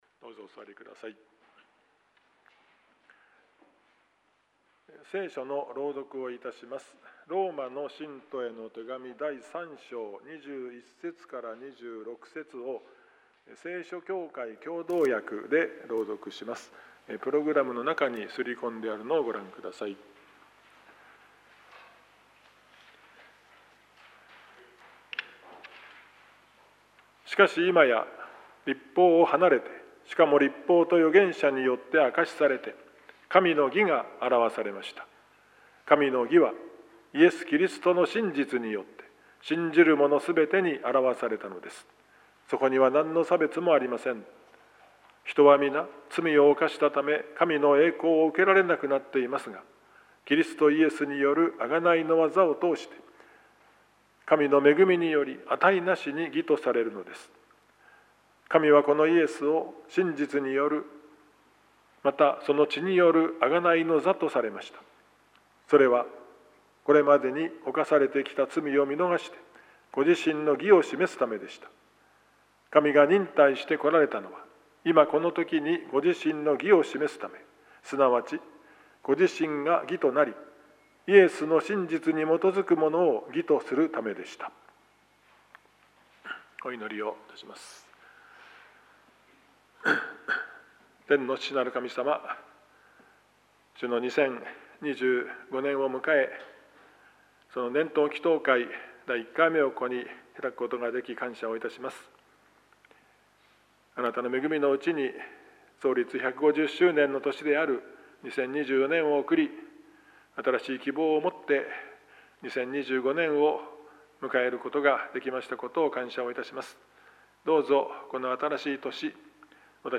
２０２５年１月の聖句についての奨励（１月８日 年頭祈祷会）